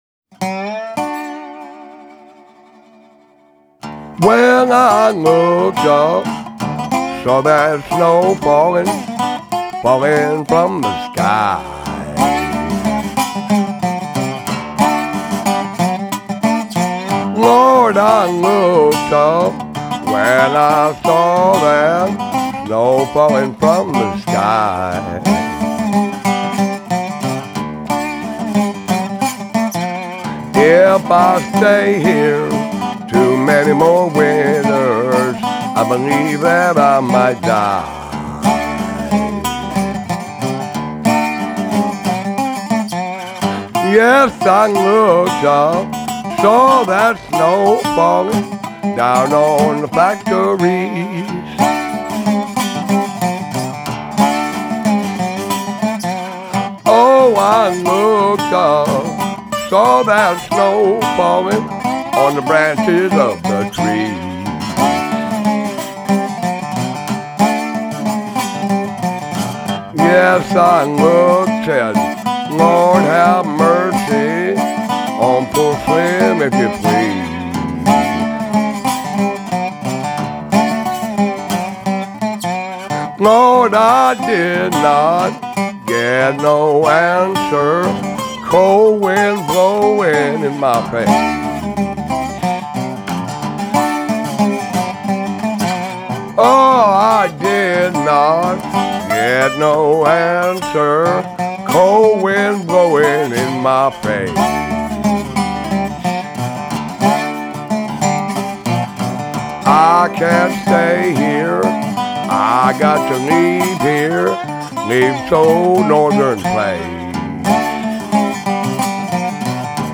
vocals/harmonica/slide guitar
upright bass
electric guitar
fiddle